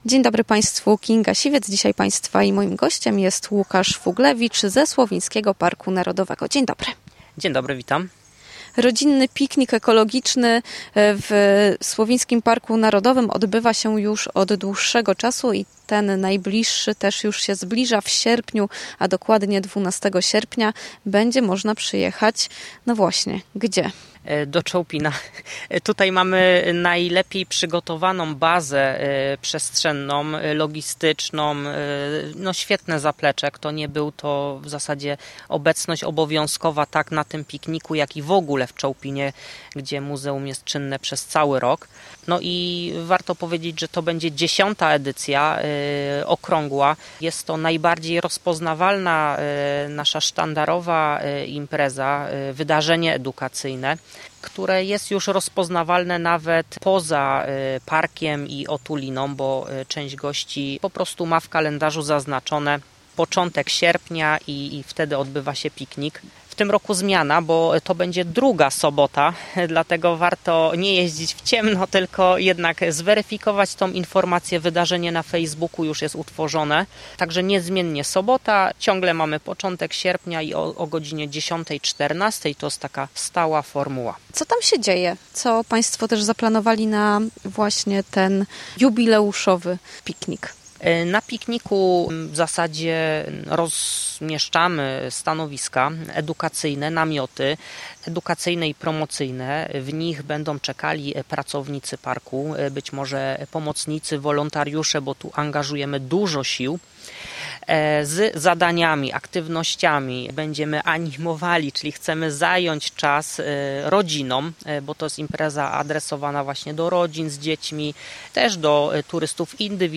rozmowie